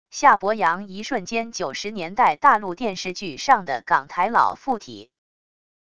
夏伯阳一瞬间90年代大陆电视剧上的港台佬附体wav音频